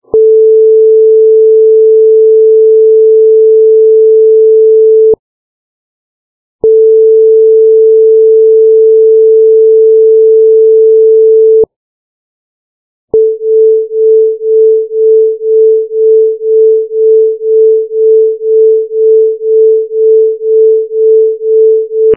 Amostras Sonoras com Batimentos
Duas frequências próximas são apresentadas separadamente nos dois primeiros sinais, e logo após, os dois sinais são apresentados superpostos, revelando a ocorrência dos batimentos.
amostra_02_com_batimentos.mp3